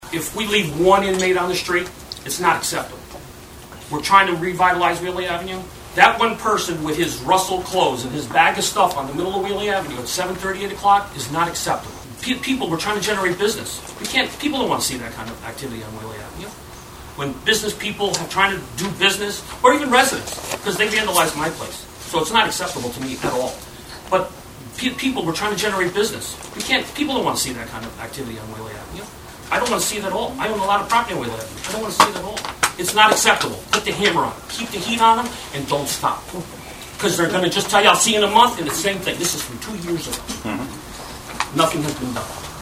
His strong statement generated a smattering of applause.